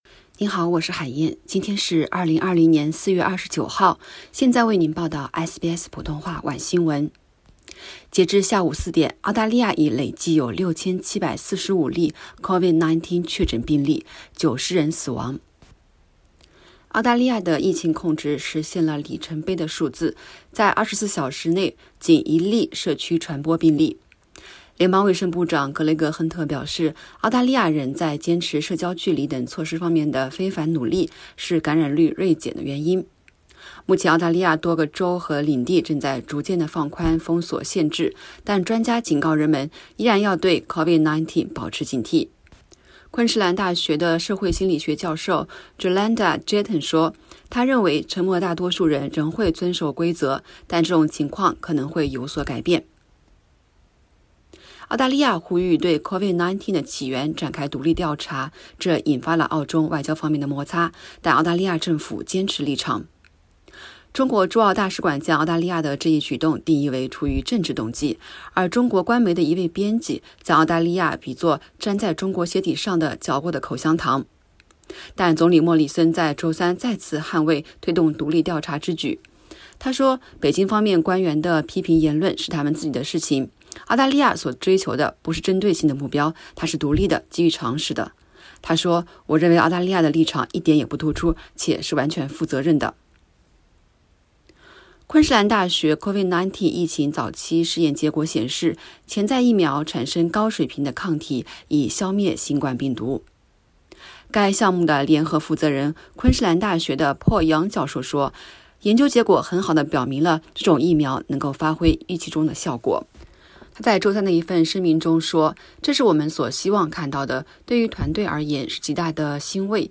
SBS晚新闻（4月29日）